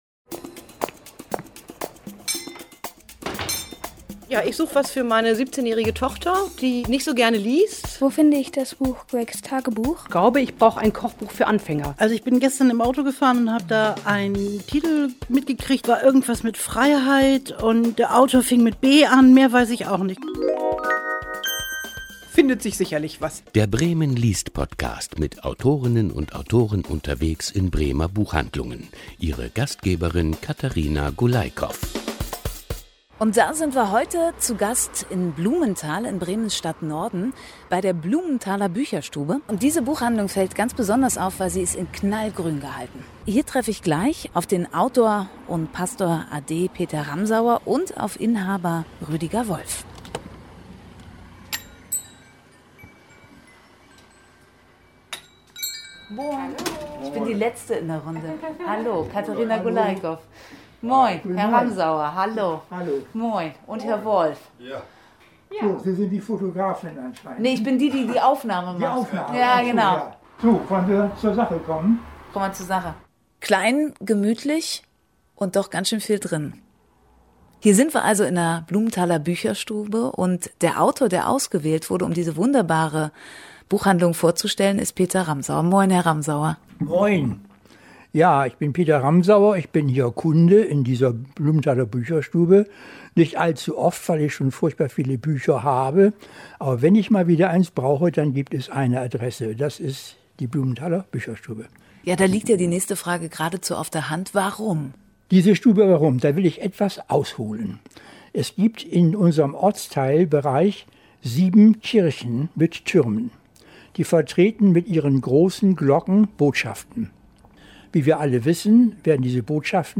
Bremen-liest-Podcast 2020: Mit Autorinnen und Autoren unterwegs in Bremer Buchhandlungen